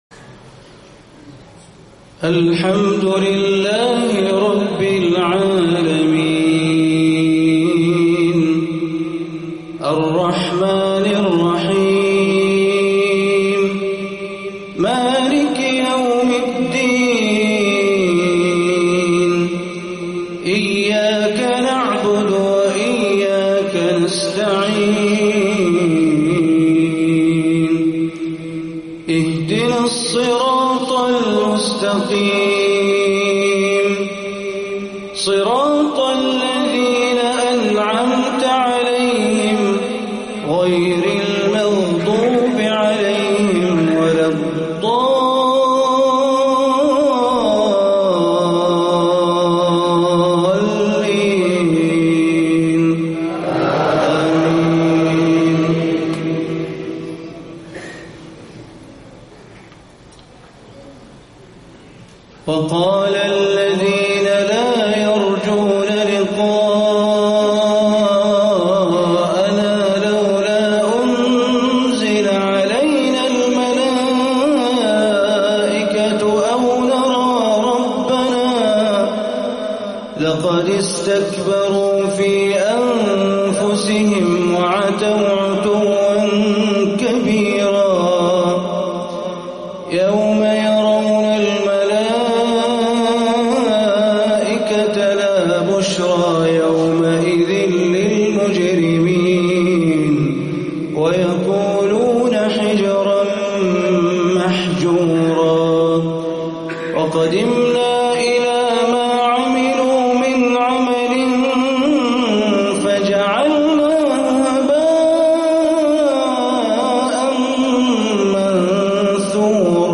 عشائية تفوق الوصف للشيخ بندر بليلة من سورة الفرقان من جامع موضي العصيمي في الخبر 7-5-1437هـ > تلاوات الشيخ بندر بليلة في المنطقة الشرقية عام 1437هـ > المزيد - تلاوات بندر بليلة